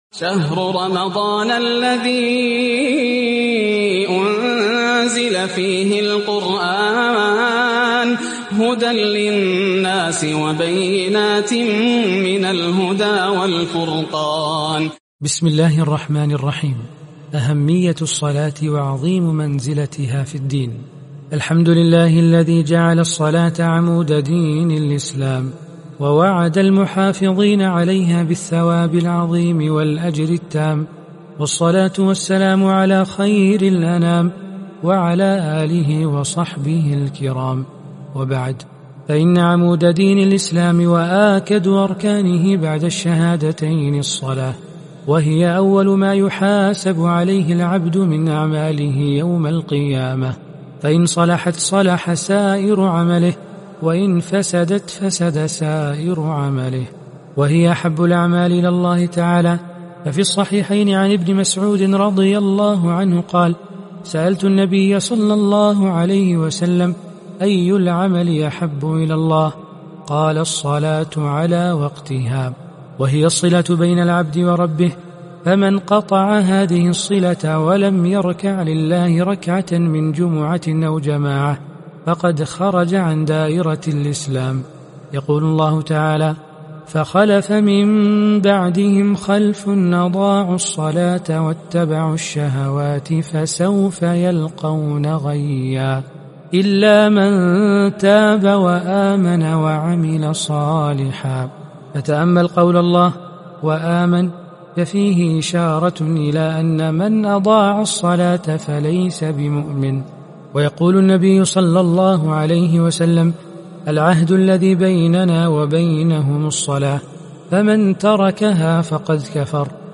(37) القراءة الصوتية لكتاب عقود الجمان - (الدرس 36 أهمية الصلاة وعظيم منزلتها في الدين) - الشيخ سعد بن تركي الخثلان